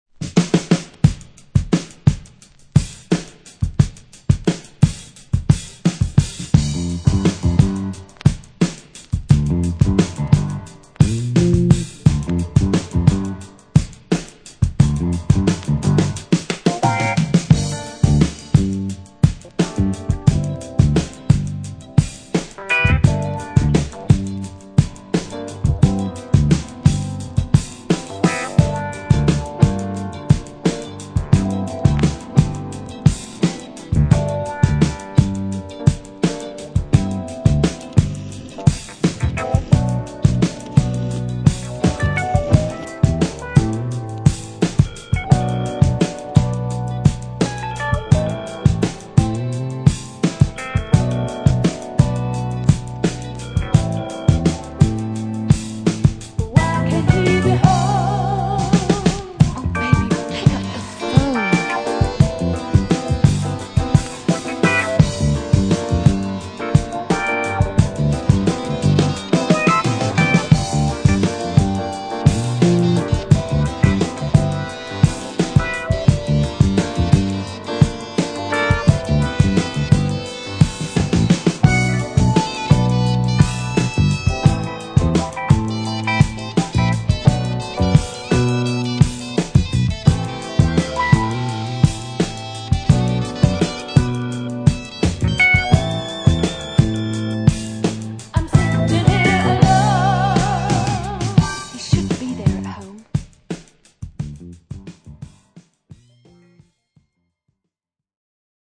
SECRET UK FUNK INSTRUMENTAL